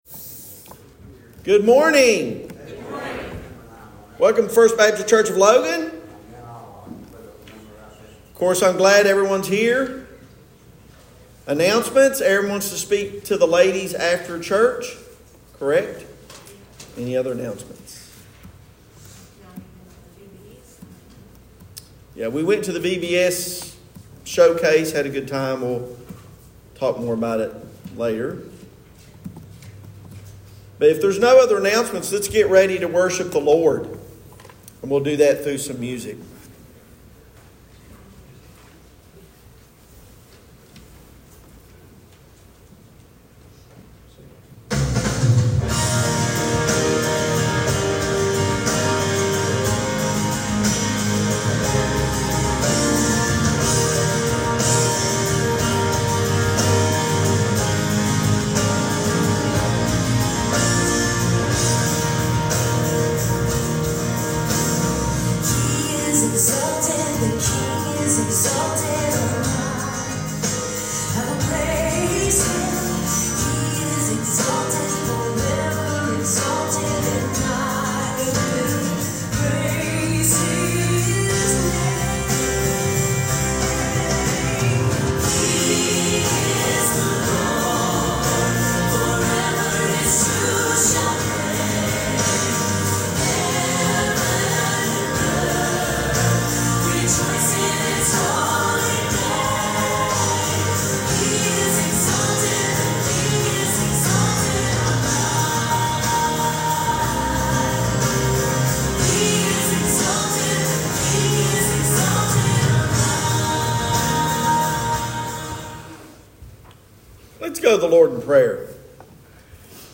Sermons | First Baptist Church of Logan